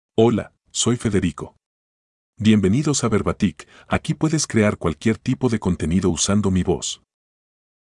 MaleSpanish (Nicaragua)
FedericoMale Spanish AI voice
Federico is a male AI voice for Spanish (Nicaragua).
Voice sample
Listen to Federico's male Spanish voice.
Federico delivers clear pronunciation with authentic Nicaragua Spanish intonation, making your content sound professionally produced.